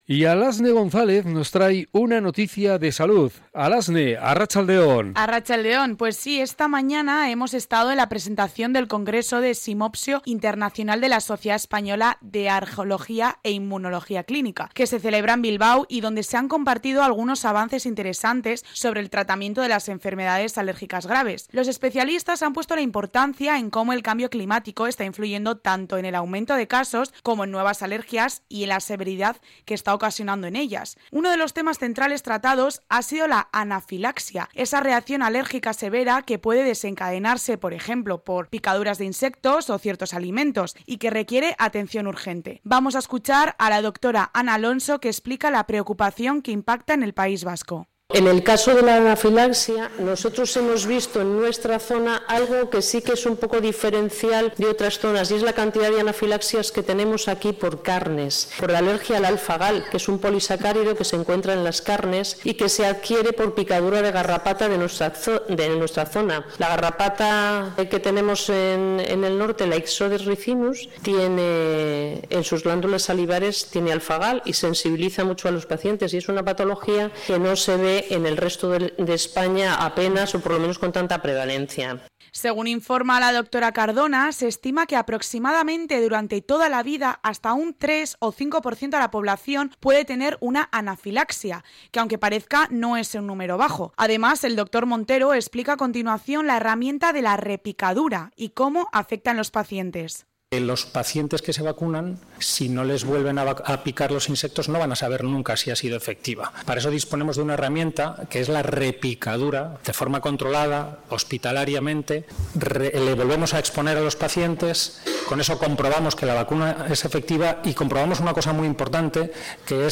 Crónica sobre la presentación del Simposio internacional